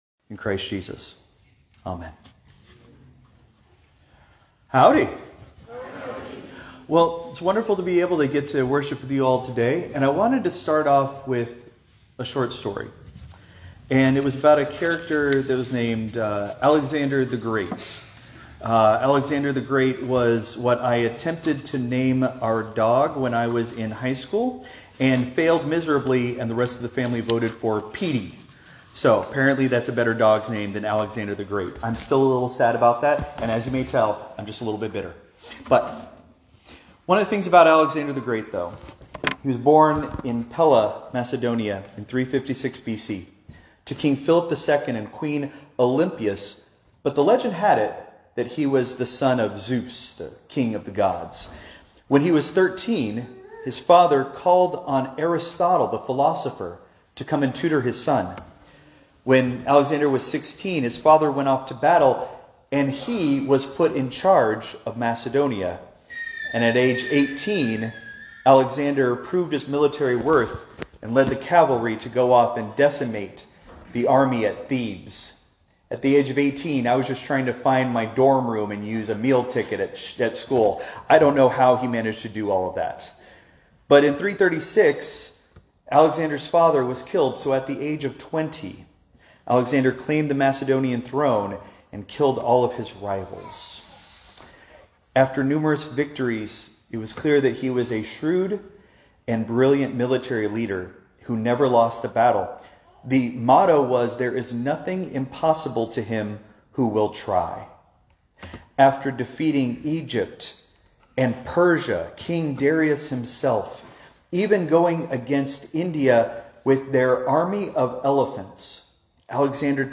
Christ Memorial Lutheran Church - Houston TX - CMLC 2025-01-26 Sermon (Contemporary)